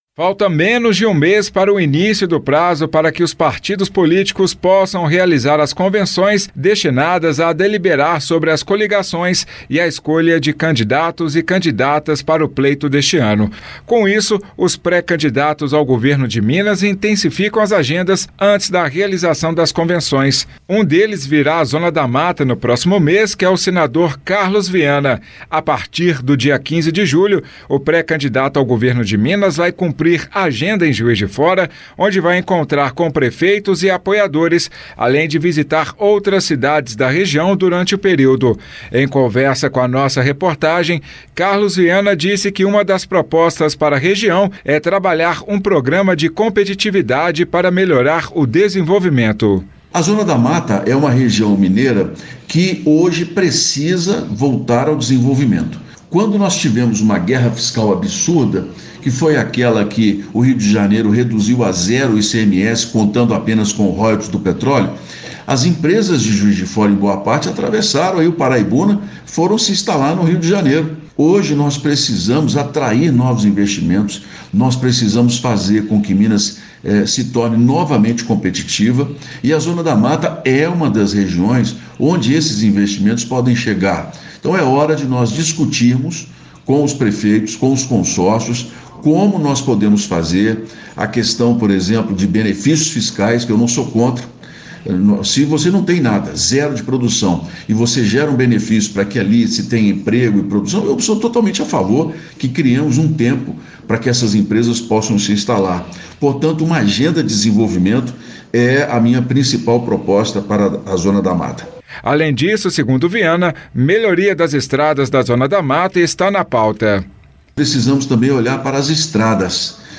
Pré-candidato ao Governo de Minas, Senador Carlos Viana fala sobre desenvolvimento da Zona da Mata e necessidade de melhorias de estradas.